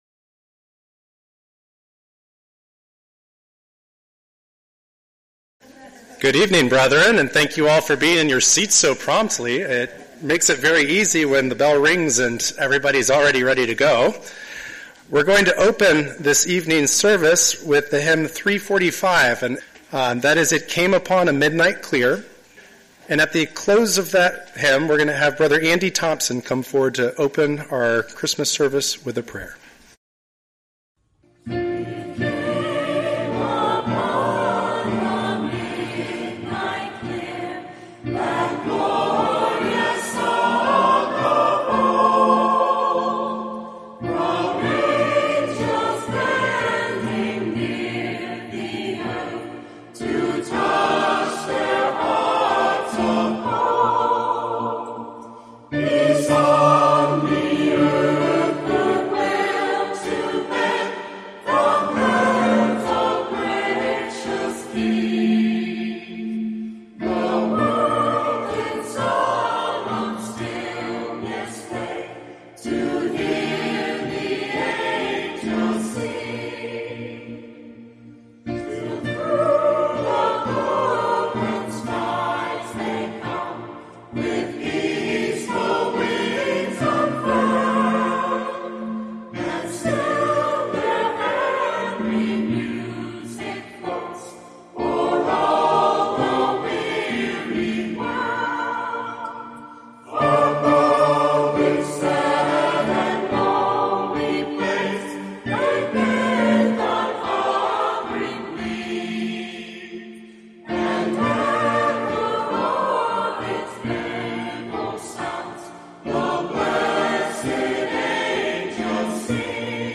Through scripture readings, hymns, and reflections, attendees were reminded of the enduring peace Jesus offers in the present and the future promise of a harmonious, restored earth under His reign. The gathering closed with prayers of gratitude and encouragement to share this message of peace and hope.